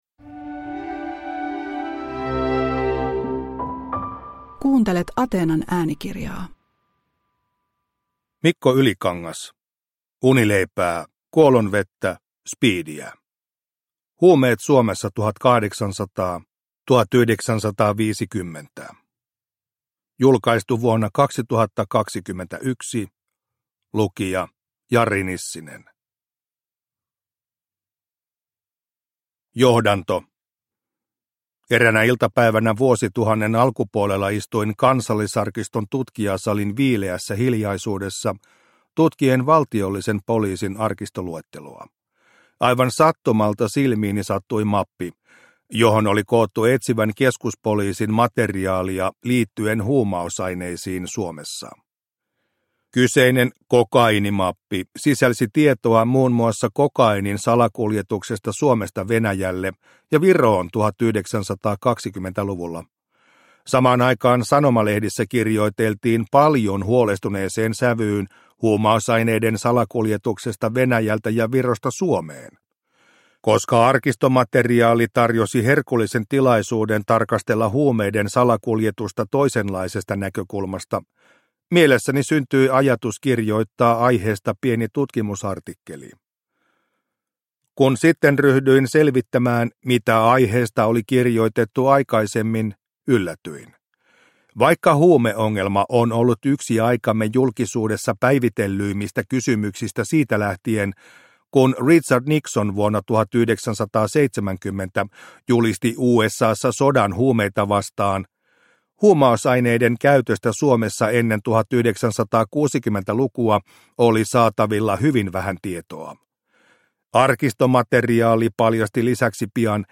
Unileipää, kuolonvettä, spiidiä – Ljudbok